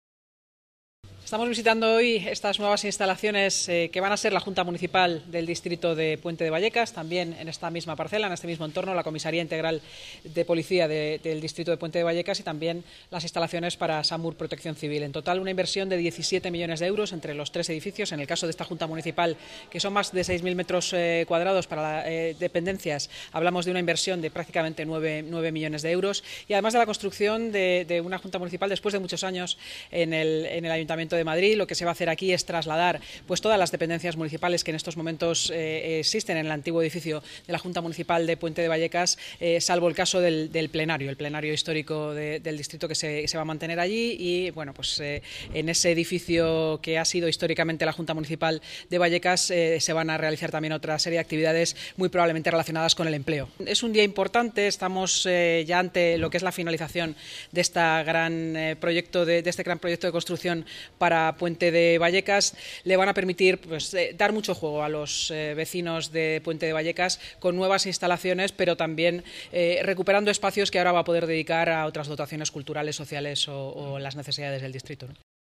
Nueva ventana:Declaraciones de la vicealcaldesa, Inma Sanz